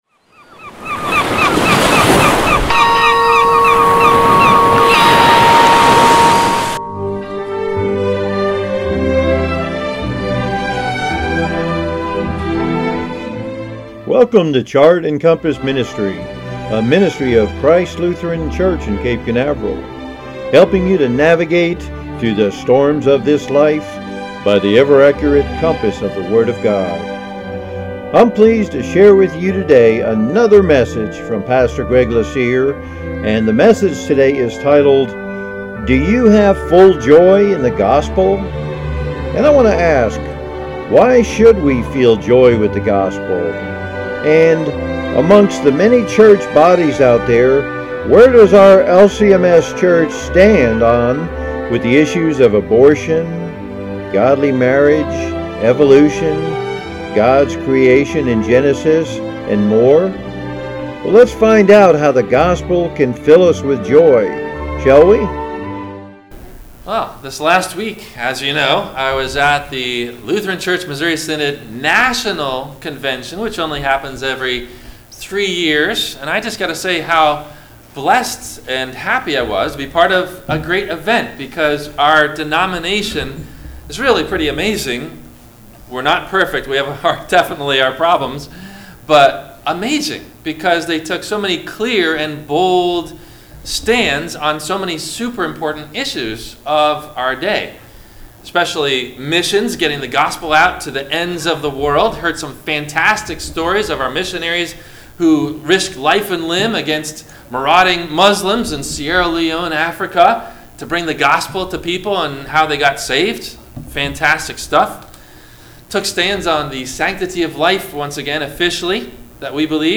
Do You Have Full Joy In The Gospel? – WMIE Radio Sermon – August 05 2019